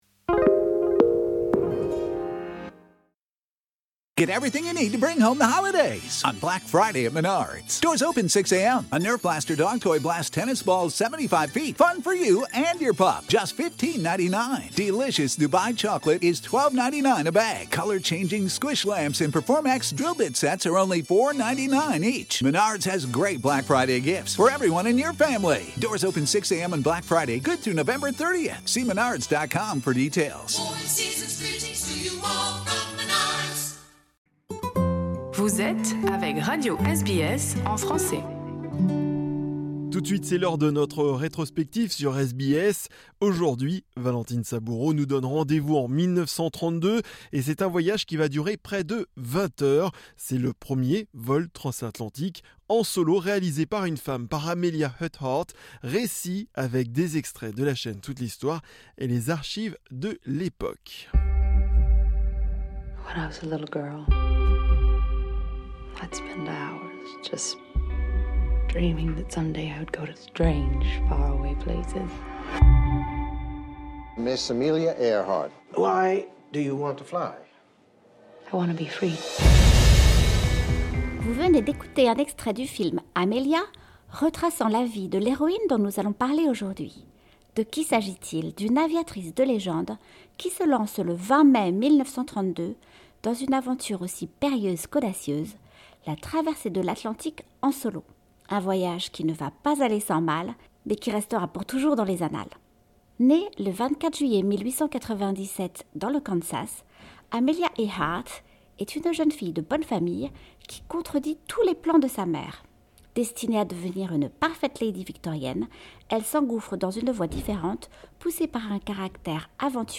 C’est le premier vol transatlantique en solo réalisée par Amelia Earhart! Récit avec des extraits de la chaîne Toute l’histoire et les archives de l’époque.